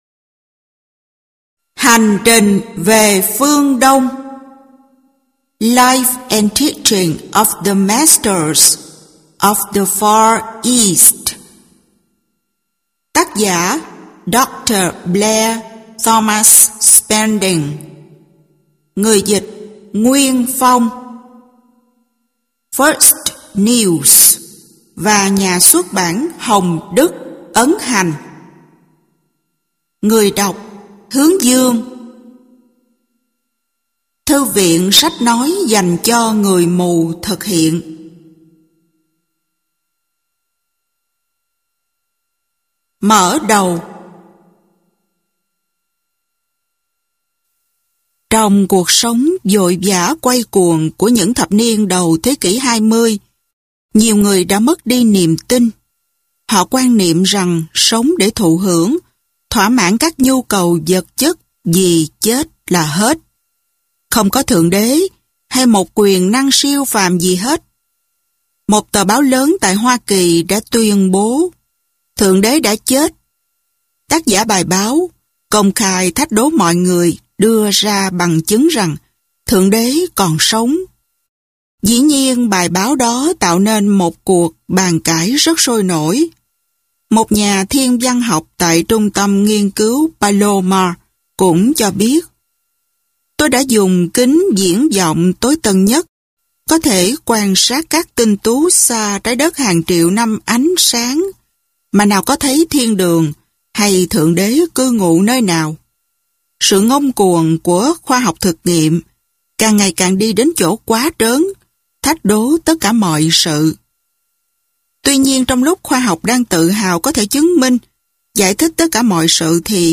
Sách nói Hành Trình Về Phương Đông - Baird T. Spalding - Sách Nói Online Hay